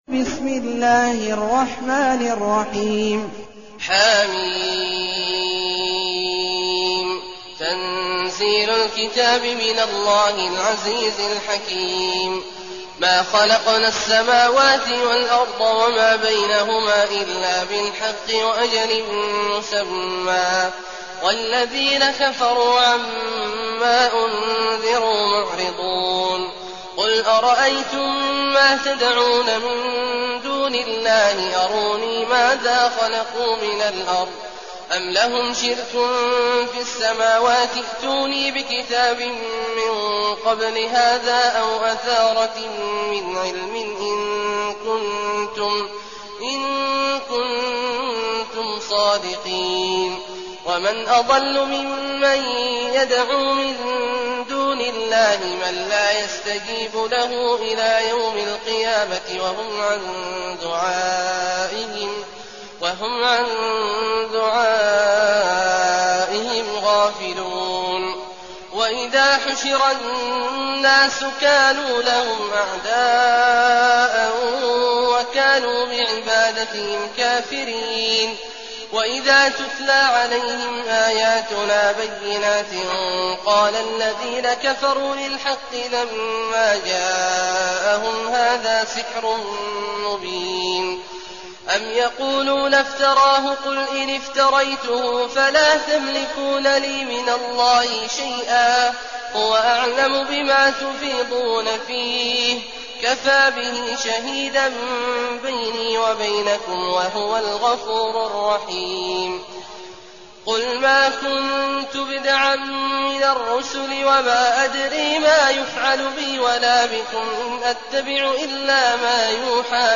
المكان: المسجد الحرام الشيخ: عبد الله عواد الجهني عبد الله عواد الجهني الأحقاف